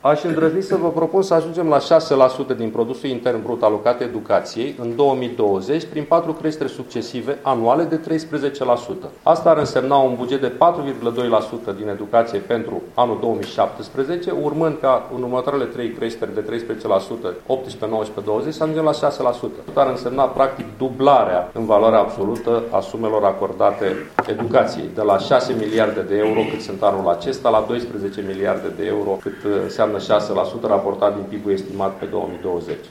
Problema subfinanțării învățământului a fost discutată la Tîrgu-Mureș, în cadrul întâlnirii Consiliului Național al Rectorilor.
Președintele acestui for, Sorin Câmpeanu, fost ministru al Educației, consider că această creștere se poate realiza treptat și că, în final, va însemna o dublare a bugetului alocat educației: